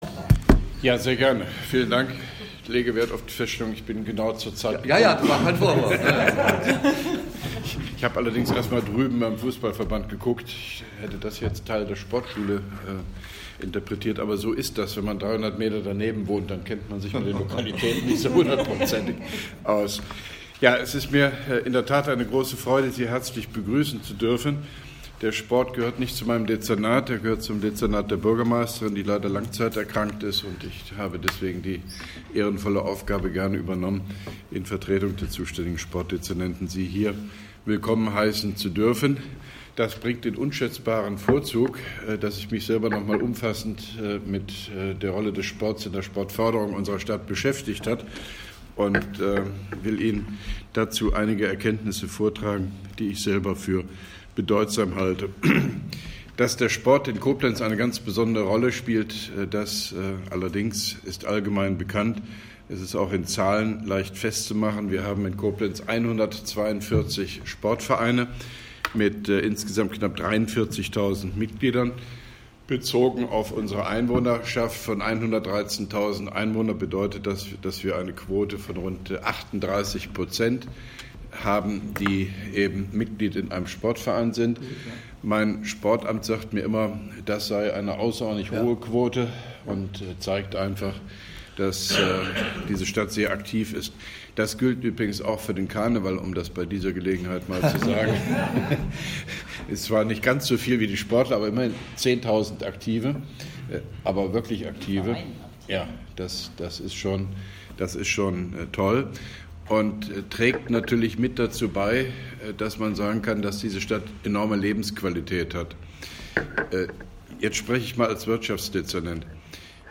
Grußwort von OB Hofmann-Göttig bei der Sitzung der ARGE der Sportverbände Rheinland-Pfalz, Koblenz 21.10.2017